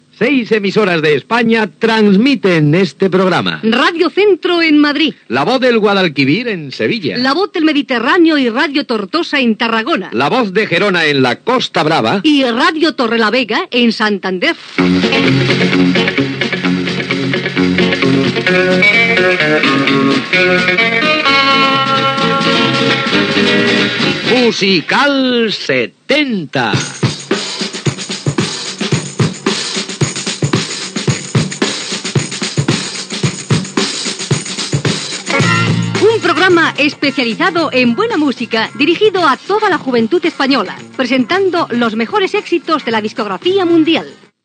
Careta del programa amb esment a les emissores que l'emeten.